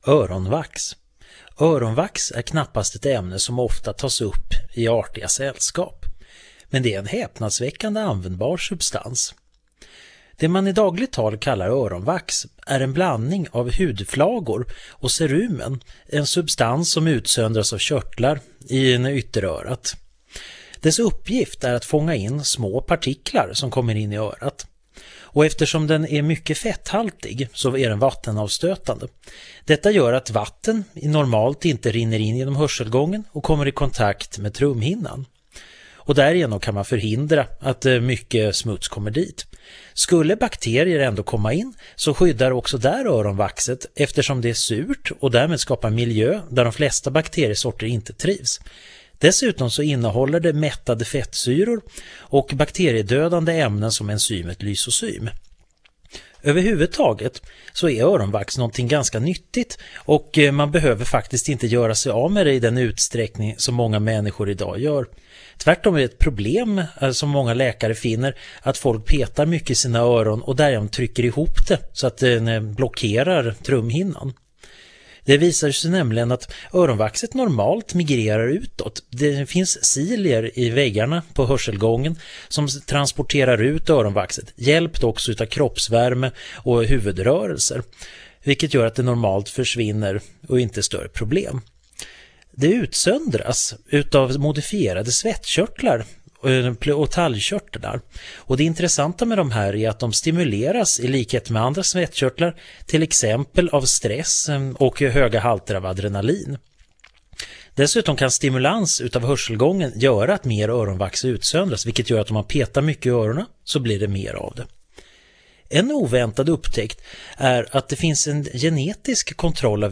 Föredraget